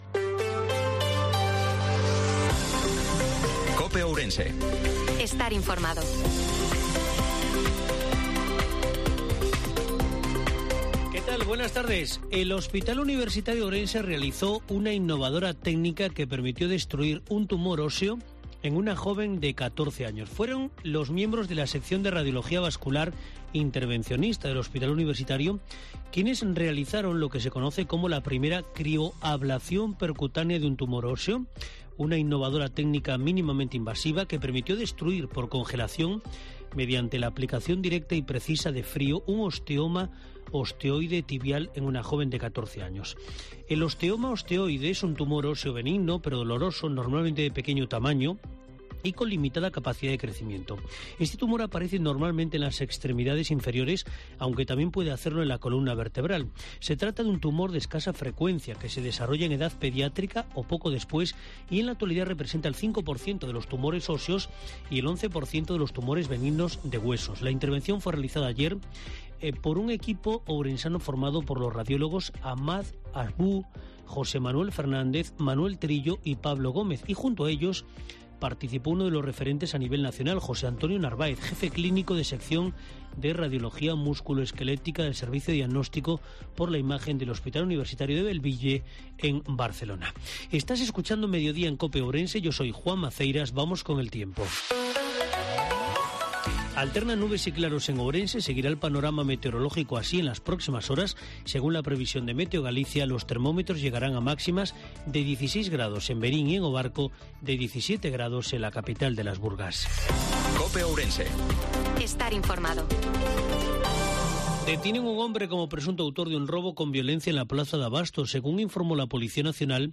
INFORMATIVO MEDIODIA COPE OURENSE-24/03/2023